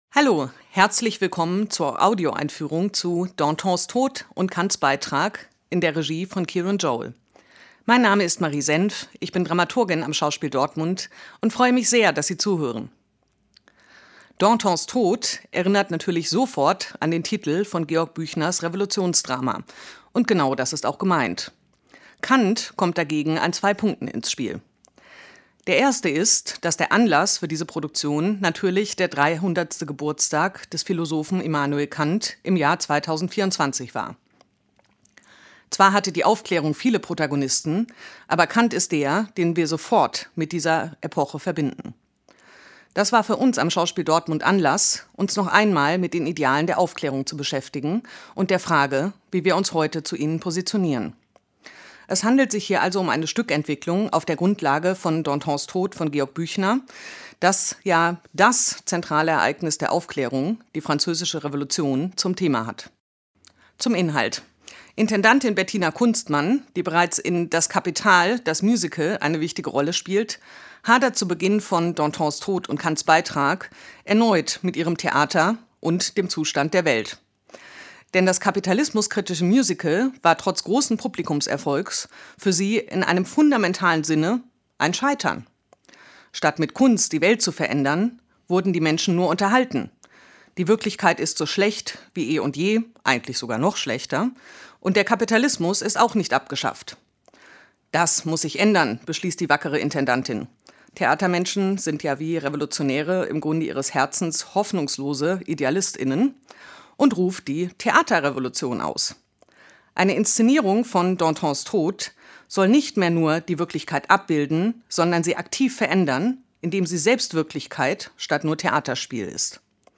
tdo_einfuehrung_danton.mp3